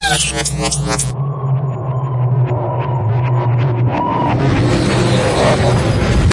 变形的声音效果 24
描述：从ZOOM H6录音机和麦克风Oktava MK01201的现场录制的效果，然后进行处理。
Tag: 未来 托管架 无人驾驶飞机 金属制品 金属 过渡 变形 可怕 破坏 背景 游戏 黑暗 电影 上升 恐怖 开口 命中 噪声 转化 科幻 变压器 冲击 移动时 毛刺 woosh 抽象 气氛